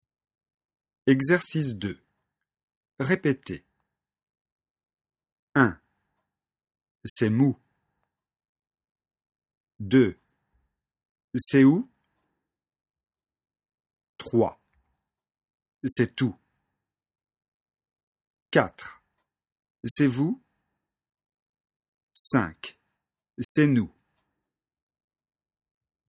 Leçon de phonétique, niveau débutant (A1).
Exercice 2 : répétez.